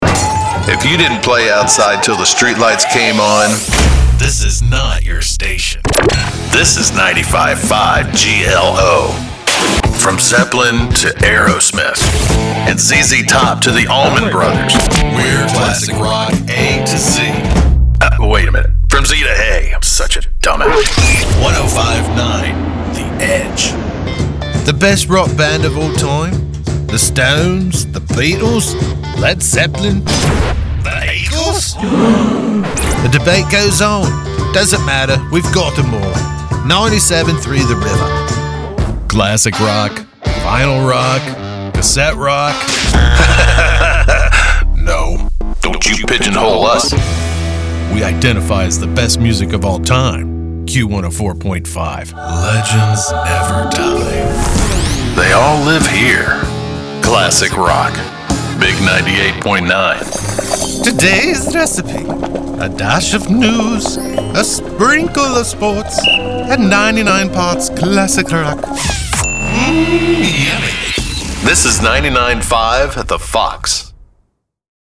Classic Rock
Classic-Rock-Demo.mp3